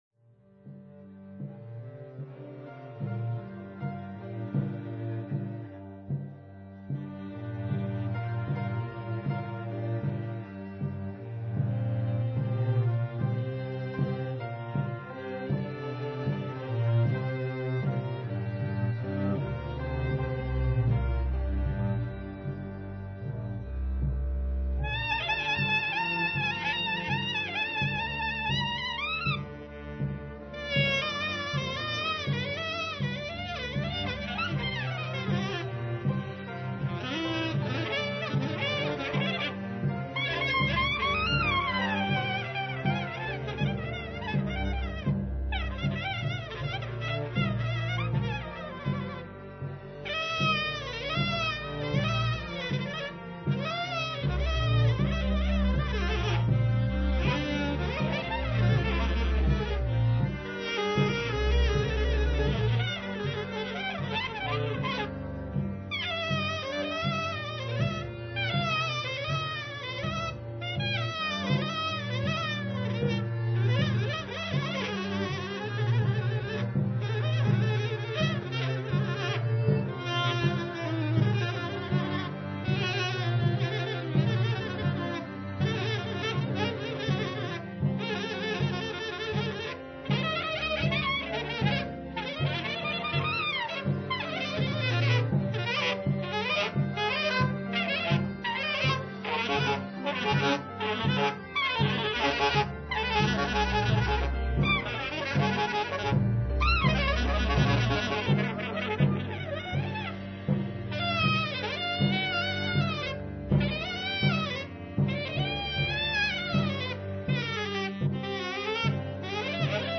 Recordings in Boston:
alto saxophone
tenor saxophone
trumpet
piano
violin
cello
bass
drums